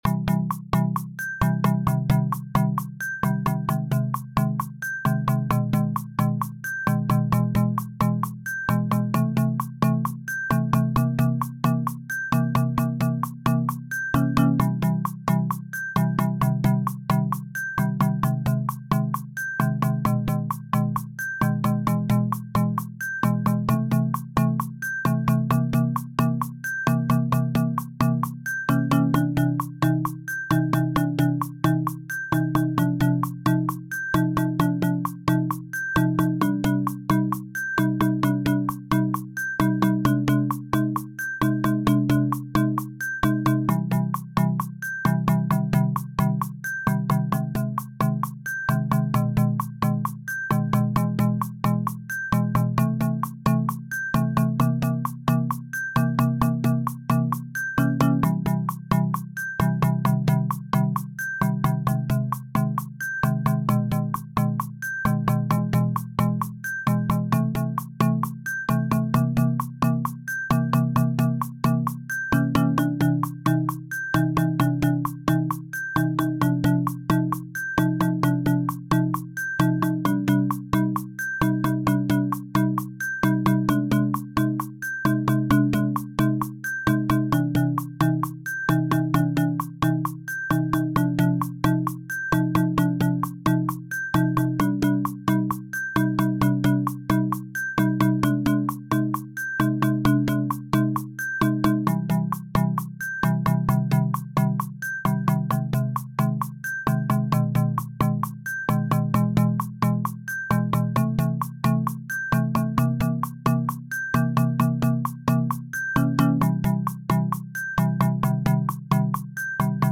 Theater Music